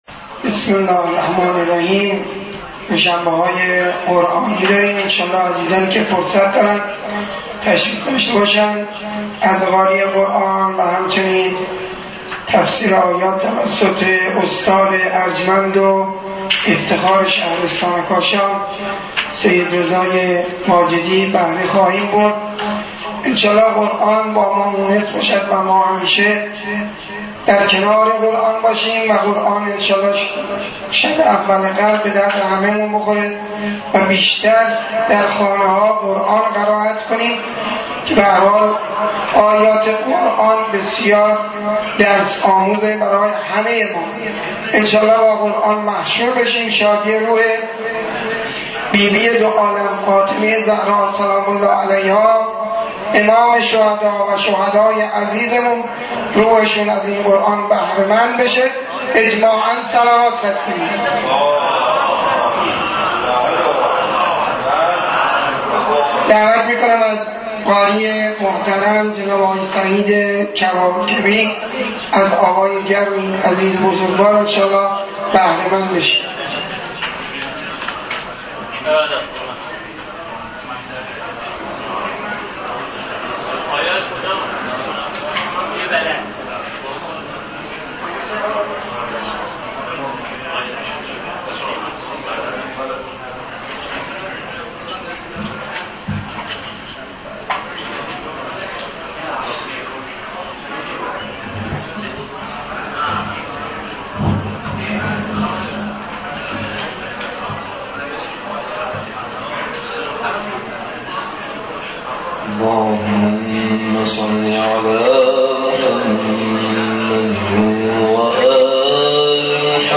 مراسم معنوی دوشنبه های قرآنی در مرکز IT دینی واقع در طبقه فوقانی مسجد دانشگاه کاشان